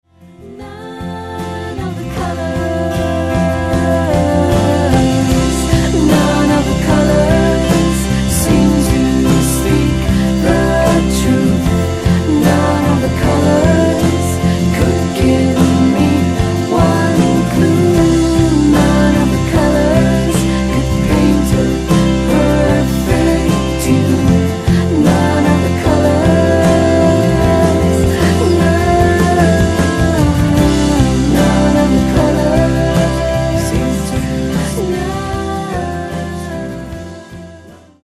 インディポップ/ワールド